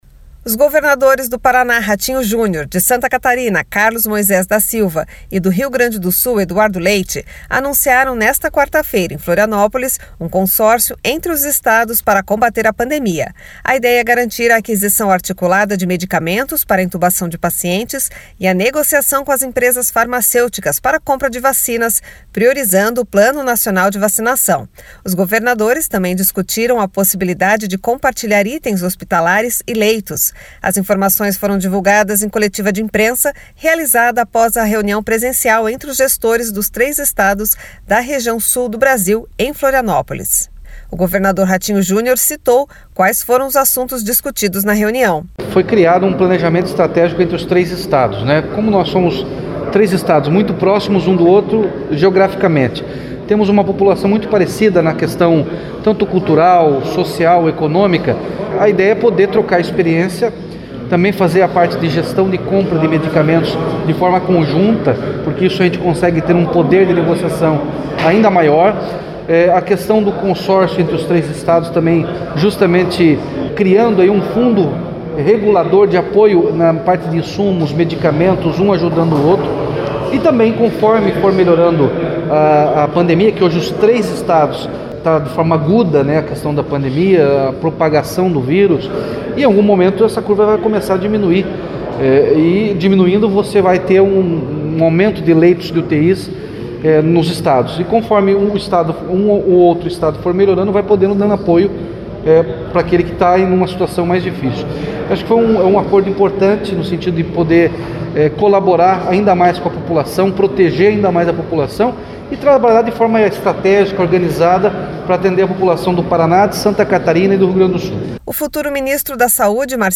As informações foram divulgadas em coletiva de imprensa realizada após reunião presencial entre os gestores dos três estados da Região Sul do Brasil em Florianópolis. O governador Ratinho Junior citou quais foram os assuntos discutidos na reunião: